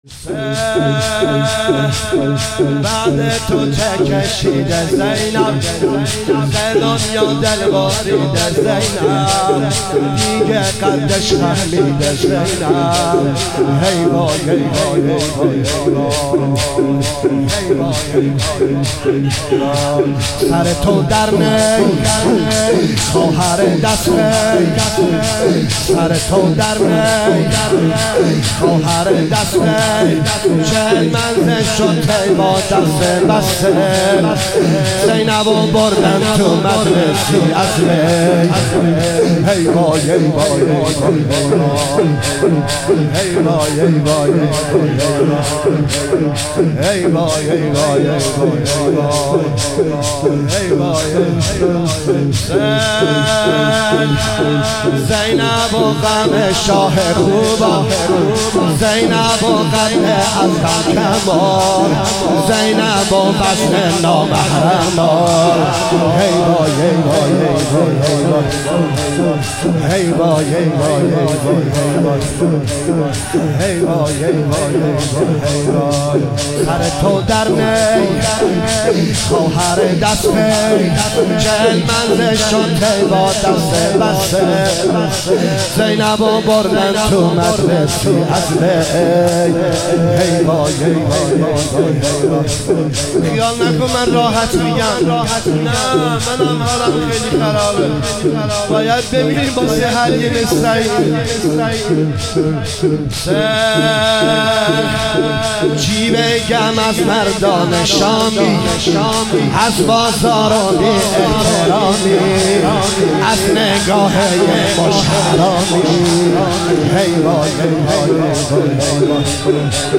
مداحی نریمان پناهی | سالروز خروج کاروان حسینی از مدینه 1439| غم خانه بی بی شهر بانو | پلان 3